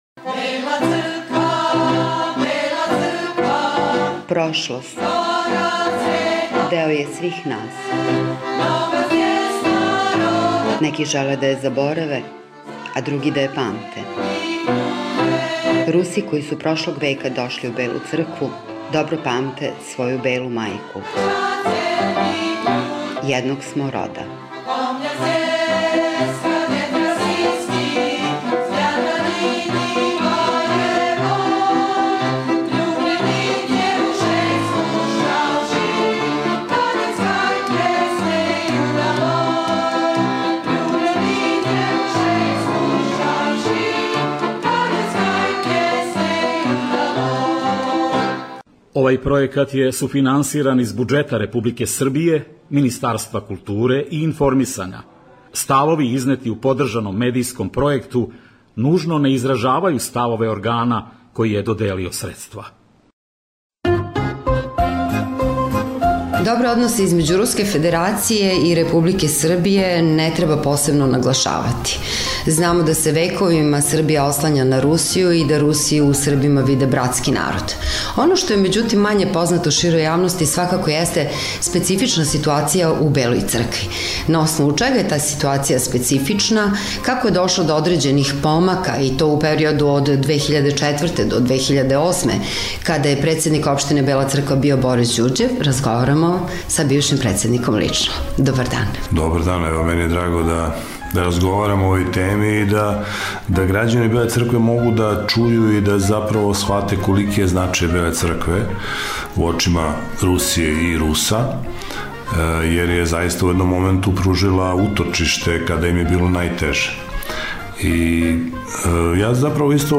Serijal emisija “Jednog smo roda” nastavljamo razgovorom sa Borisom Đurđevim, bivšim predsednikom opštine Bela Crkva.